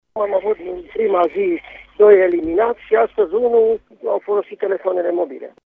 Conform Inspectorului General Școlar al județului Mureș, Ioan Macarie, până acum 3 elevi mureșeni au fost eliminați din examenul de bacalaureat pentru tentativă de fraudă: